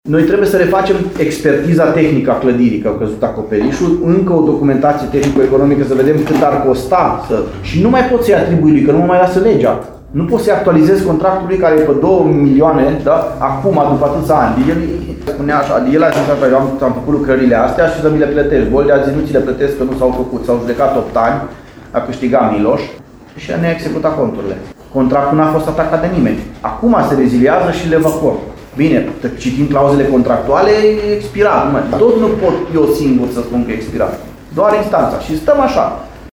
Primarul Lugojului, Claudiu Buciu, spune că este necesară și realizarea expertizei tehnice a clădirii.